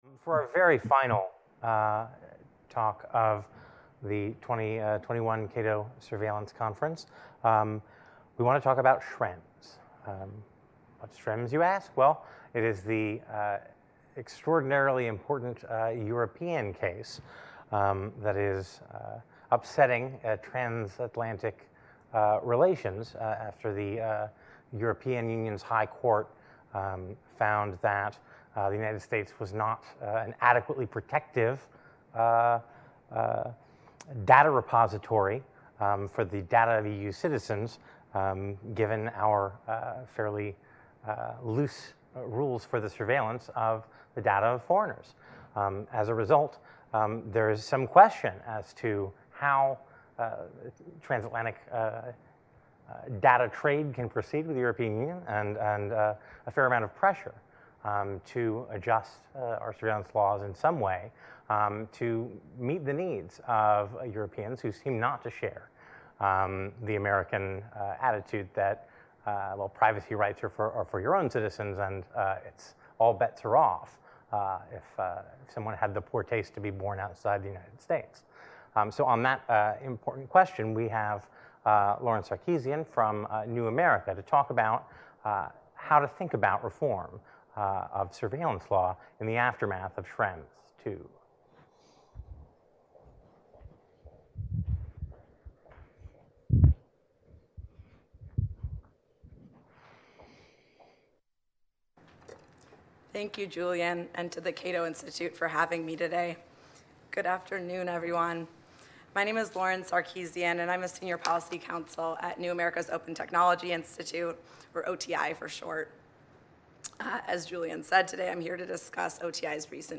Join us live online, streaming from the Cato Institute.